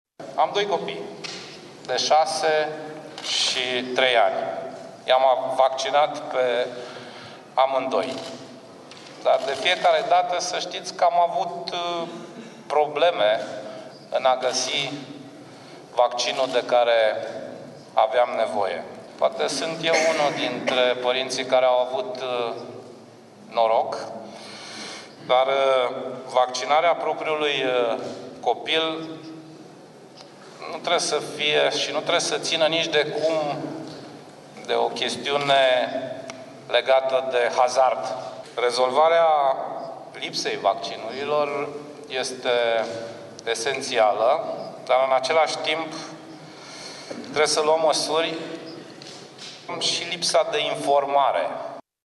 Premierul Sorin Grindeanu a declarat, joi, la o dezbatere pe tema imunizării, că şi-a vaccinat ambii copii, deşi a avut “probleme” în a găsi vaccinul de care avea nevoie, şi a precizat că susţine ideea că părinţii pot alege ce consideră că este mai bine pentru copiii lor, dar nu şi în cazul în care aceste alegeri îi afectează pe cei din jur, aşa cum se întâmplă în absenţa imunizării.
grindeanu-despre-vaccinare.mp3